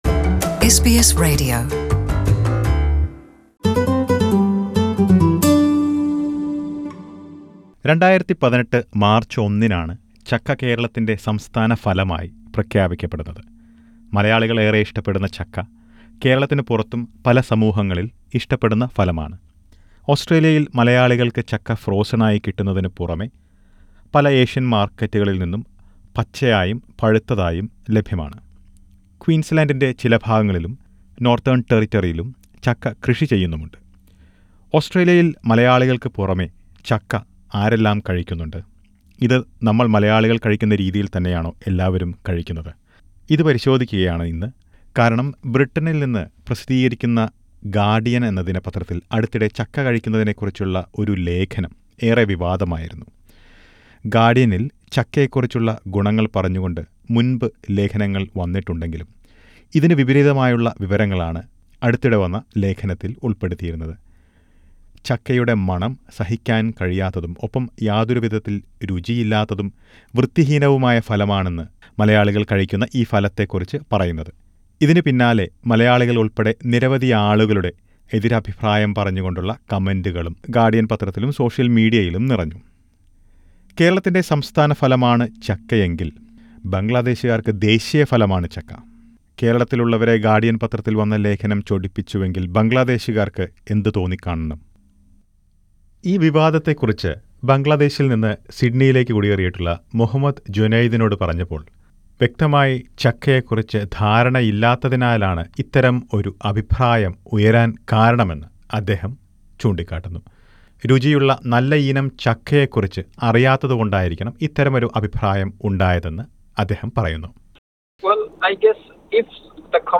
Listen to members of different communities who consume jackfruit in Australia about what they feel about the fruit and how they eat the fruit.